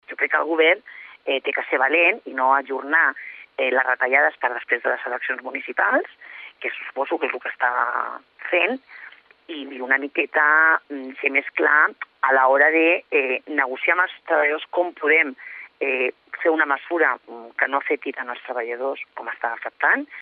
Declaracions a COM Ràdio